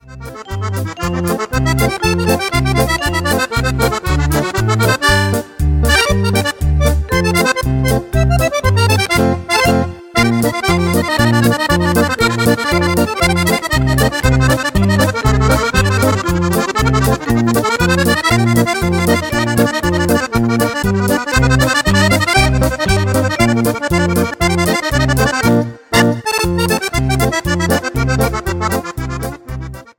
POLKA  (02.16)